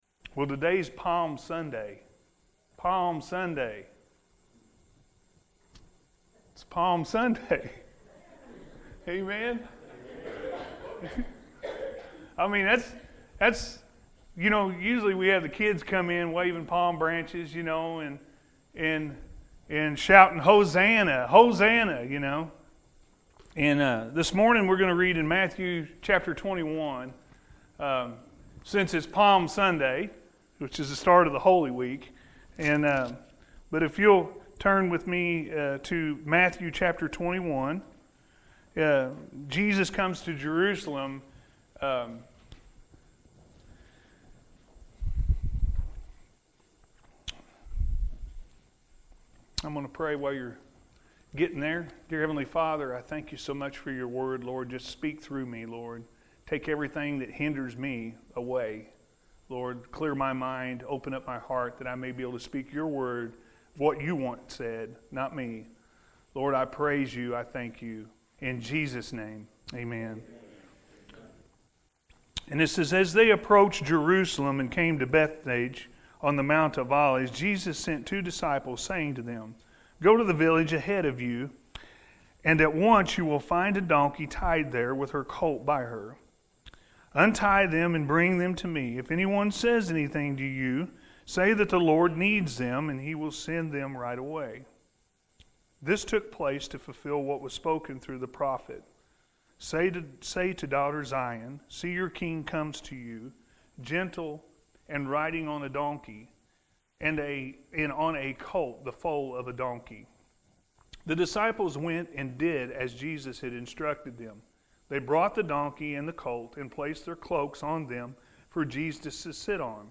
Palm Sunday/Hosanna-A.M. Service